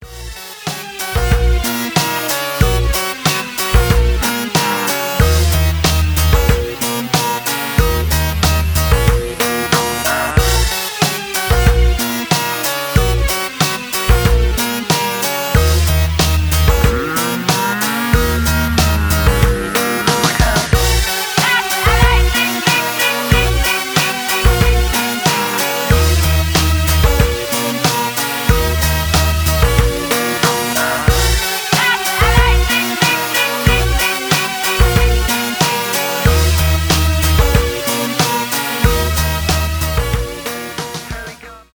electro-funk , поп , electropop
alternative , synth pop